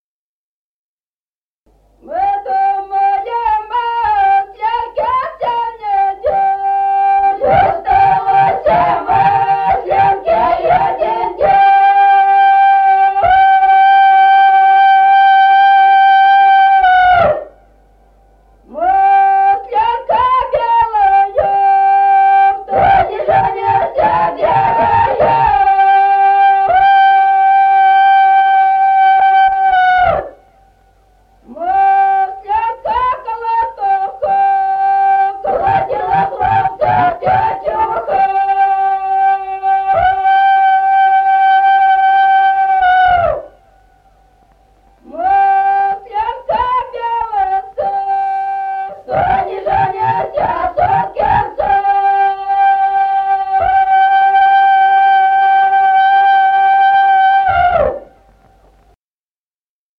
Народные песни Стародубского района «Мы думали, масленке», масленичная.
1953 г., с. Остроглядово.